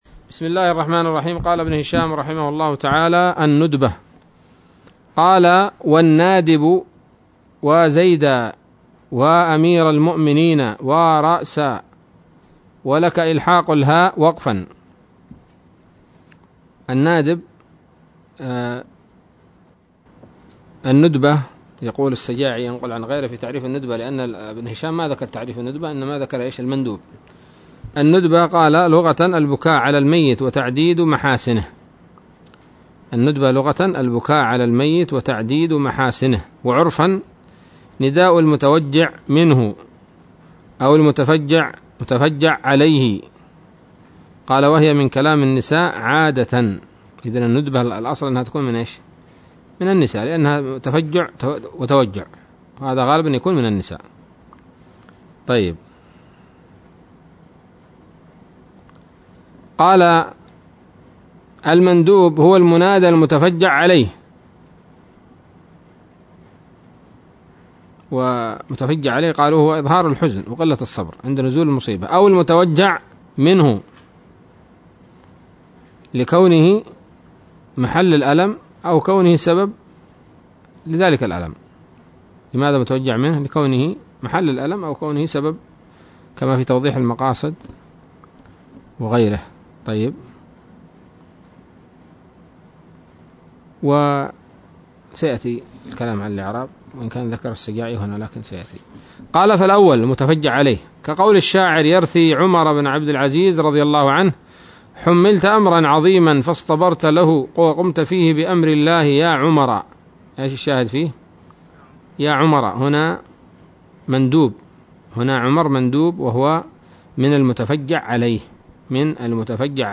الدرس الثاني والتسعون من شرح قطر الندى وبل الصدى